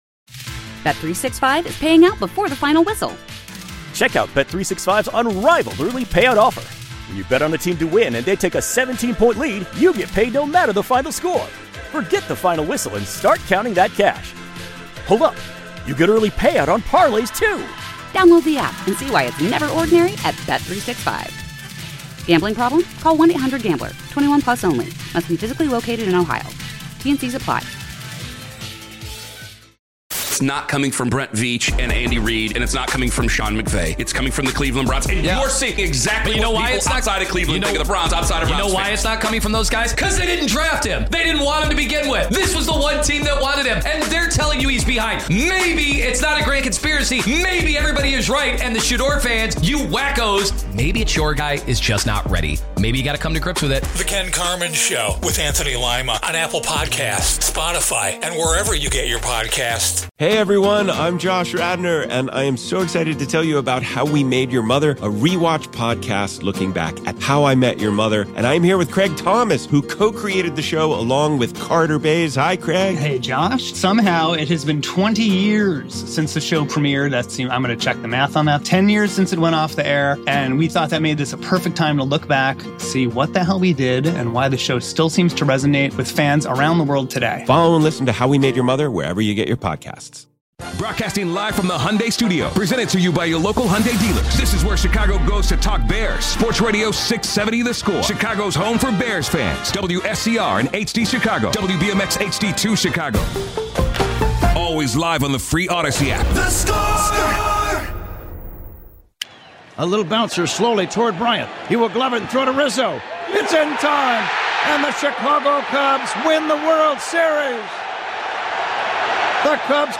Joe Maddon interview (Hour 2)